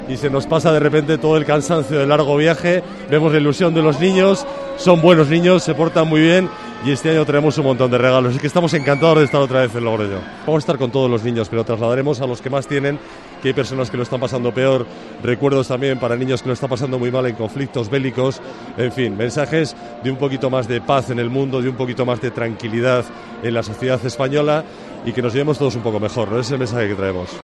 Rey Gaspar